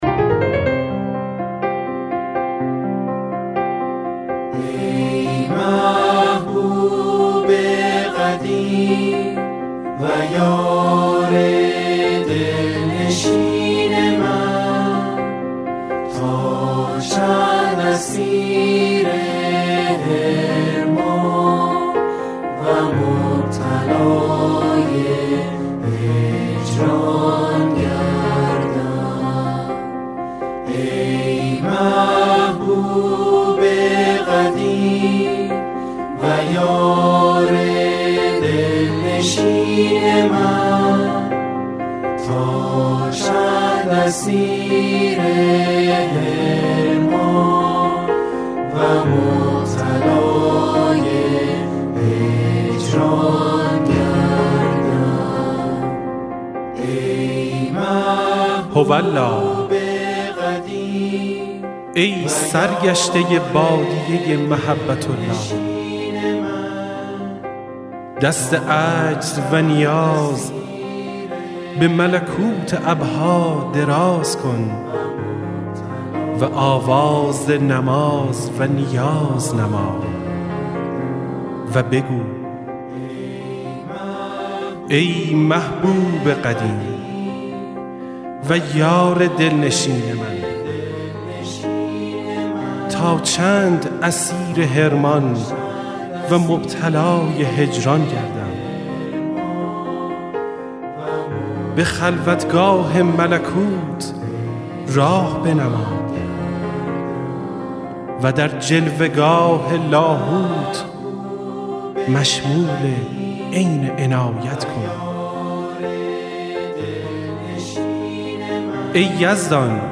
دعا و نیایش با موسیقی